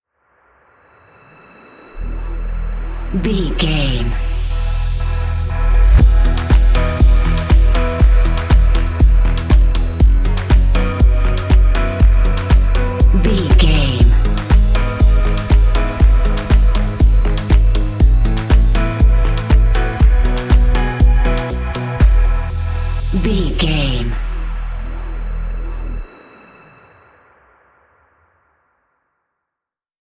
Ionian/Major
Fast
uplifting
upbeat
electronic
energetic
piano
synth
drum machine